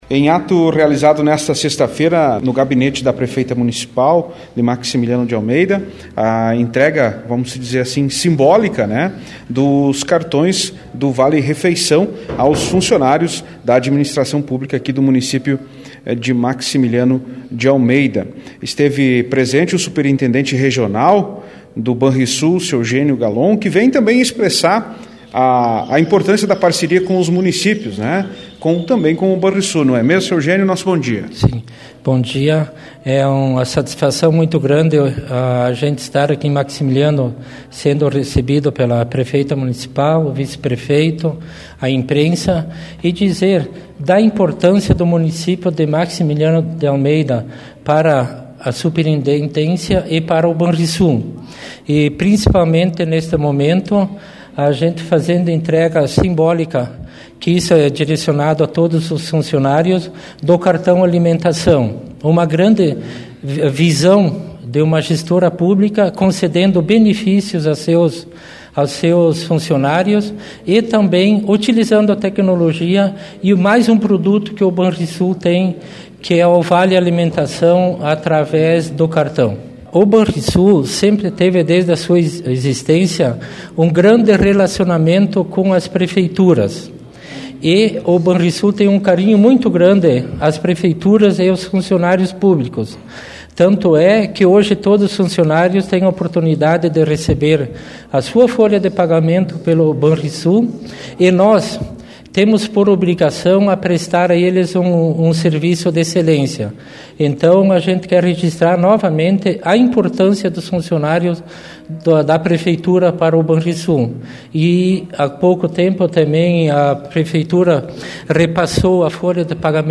Em entrevista a Rádio Interativa